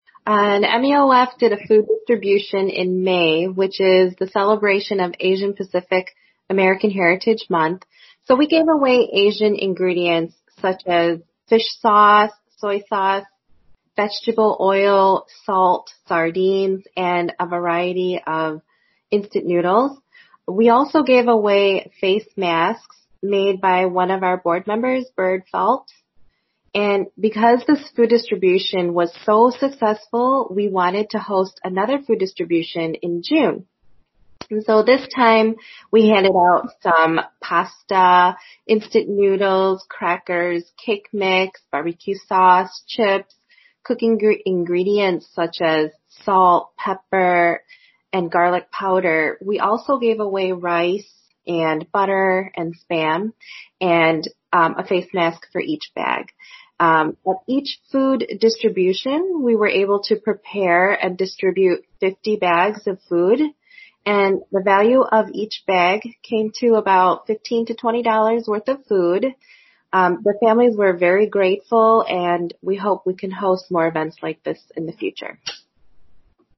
ແມ່ຍິງອາເມຣິກັນ ເຊື້ອສາຍກໍາປູເຈຍ